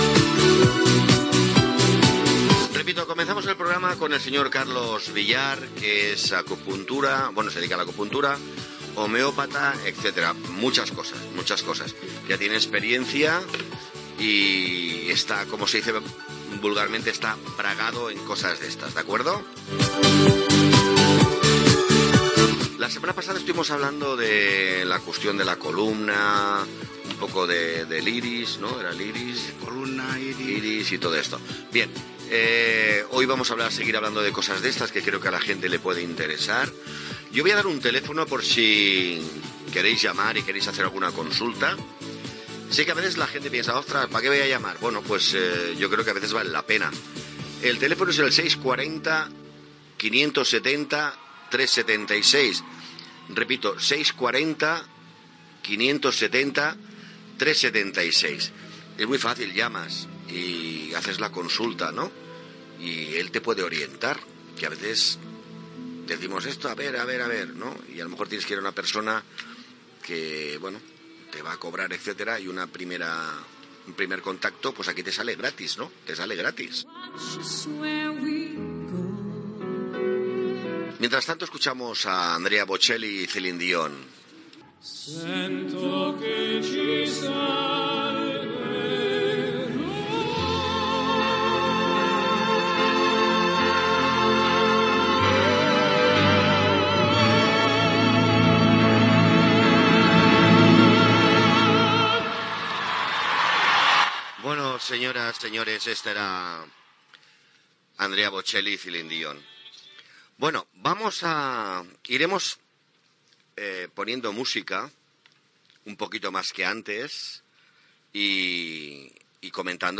Presentació, telèfon, tema musical